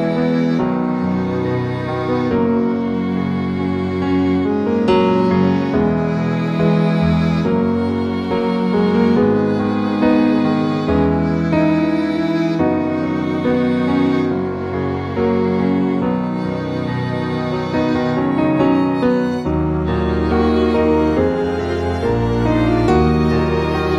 With Lead Vocals